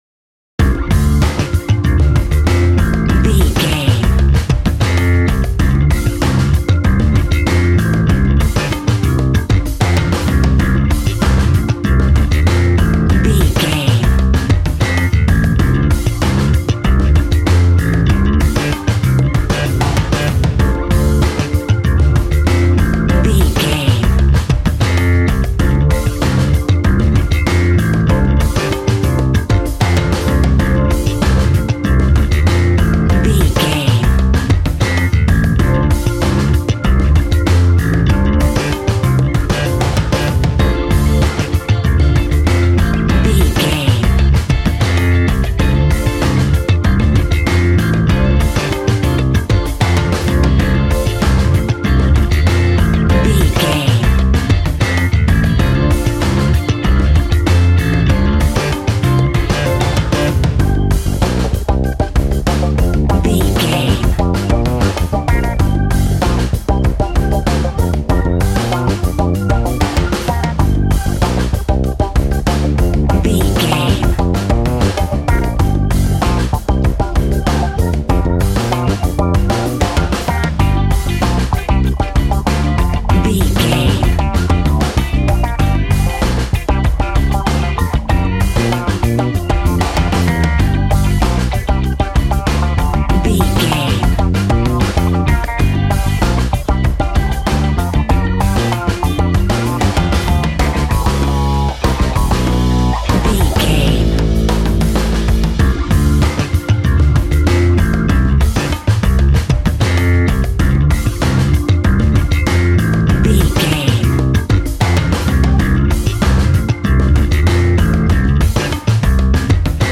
Aeolian/Minor
relaxed
smooth
synthesiser
drums
80s